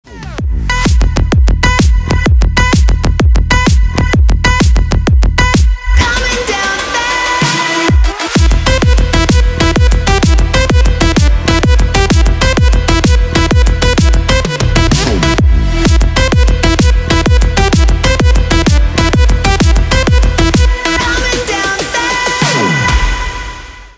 Отрывки клубной музыки